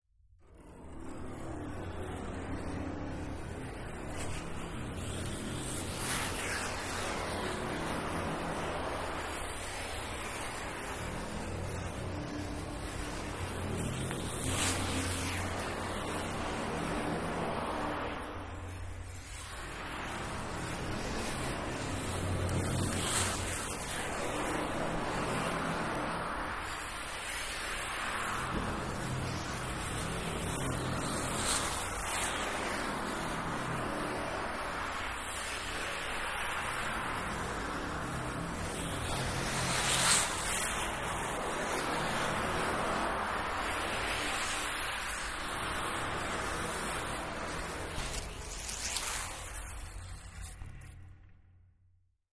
Metal Groan Atmos
Frame Drum Mtl Scrape 1